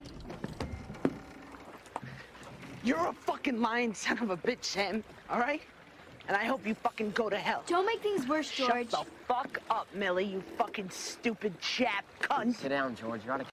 Play, download and share Josh swearing 2 original sound button!!!!
josh-peck-swearing-mean-creek_trim-1-1.mp3